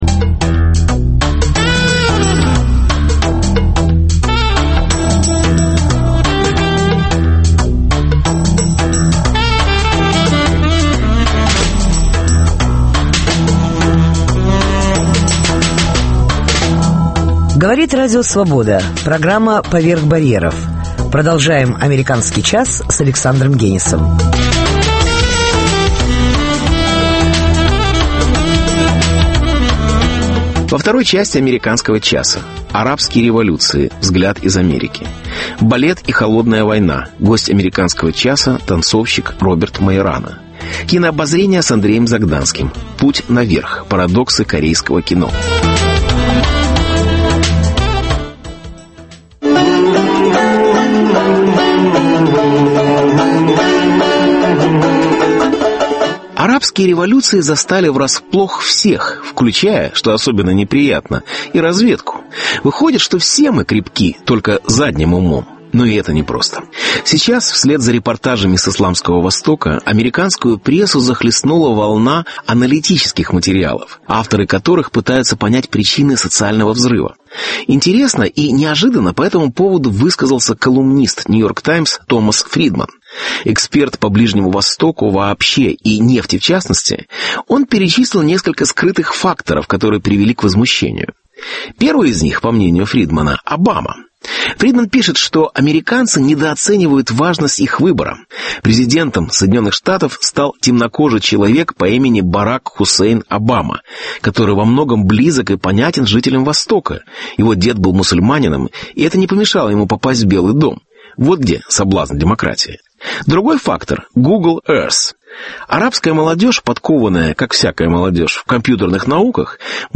Арабские революции. Взгляд из Америки. Беседа с Борисом Парамоновым.